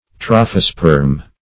Search Result for " trophosperm" : The Collaborative International Dictionary of English v.0.48: Trophosperm \Troph"o*sperm\, n. [Gr.